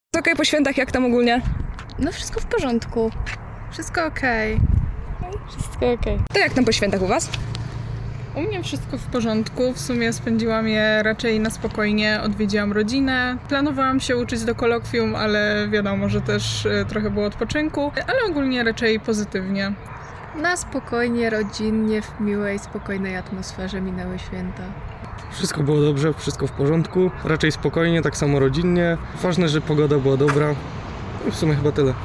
Święta, święta i po świętach – Pytamy studentów co u nich?
sonda-swieta-23.04.mp3